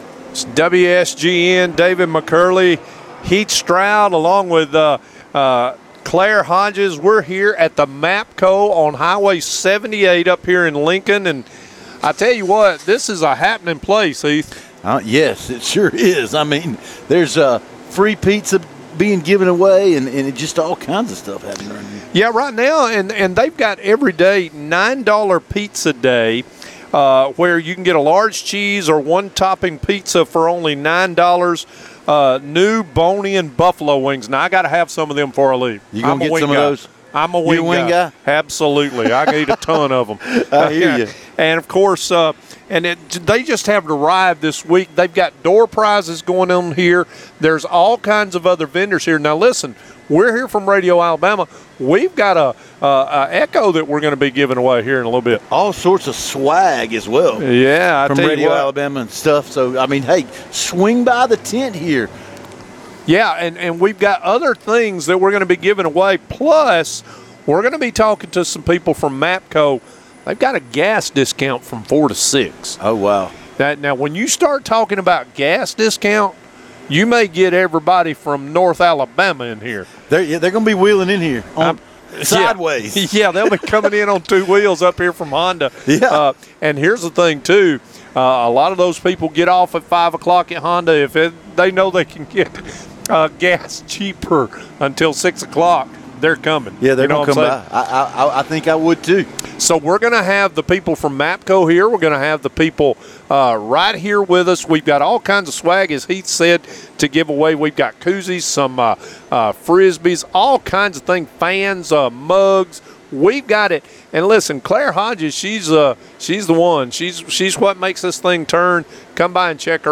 Live from the MAPCO in Lincoln